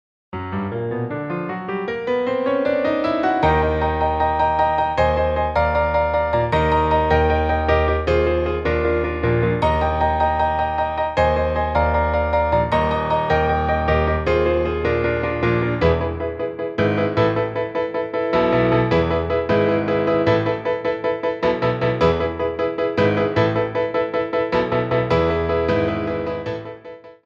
Ronds de Jambé en L'air
4/4 (8x8)